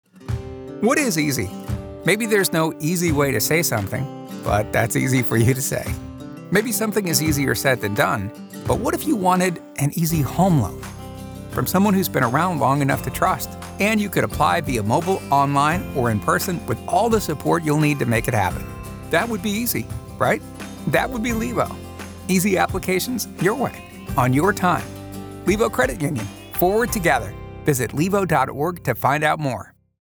0:30 "The Easy Way" Commercial